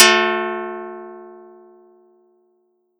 Audacity_pluck_10_14.wav